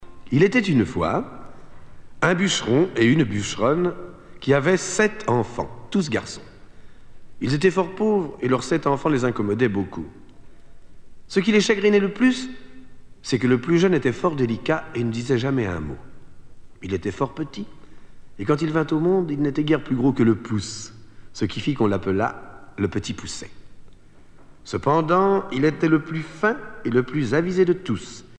Genre conte
Catégorie Récit